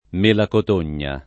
vai all'elenco alfabetico delle voci ingrandisci il carattere 100% rimpicciolisci il carattere stampa invia tramite posta elettronica codividi su Facebook mela cotogna [ m % la kot 1 n’n’a ] (meno com. melacotogna [ id.